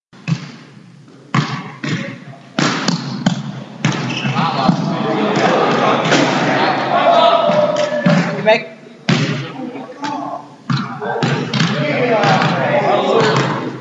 网球网
描述：记录通过网络的无板篮球
标签： 运动 无挡板篮球 网络 现场 记录
声道立体声